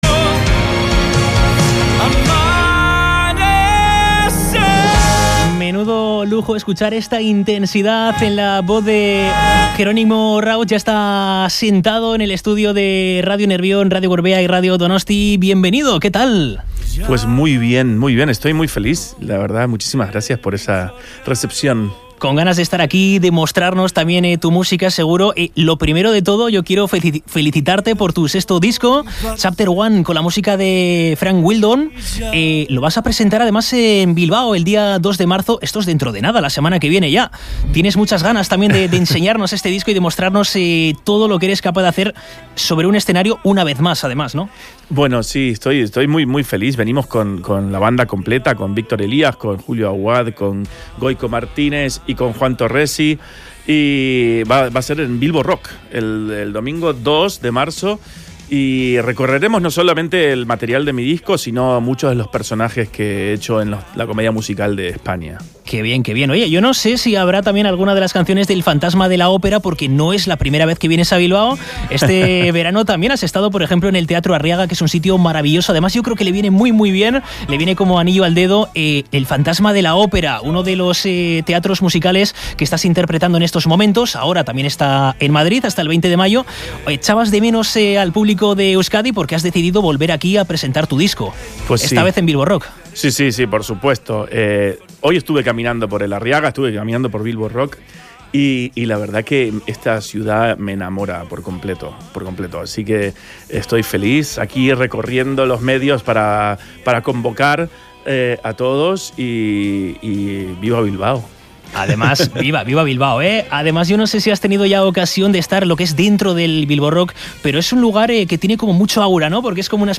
Entrevista a Gerónimo Rauch (19/02/2025)